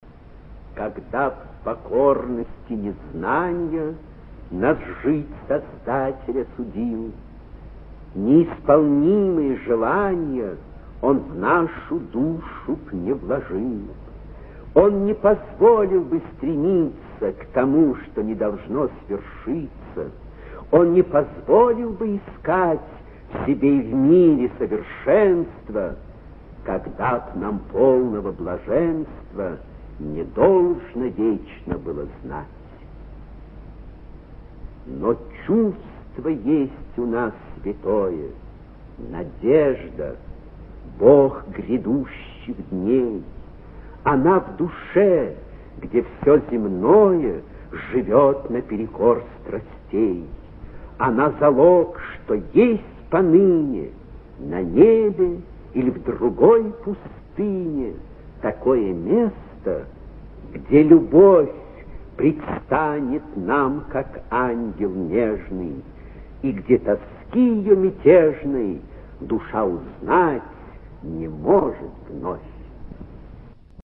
Прослушивание аудиозаписи стихотворения «Когда б в покорности незнанья...» с сайта «Старое радио». Исполнитель М. Астангов.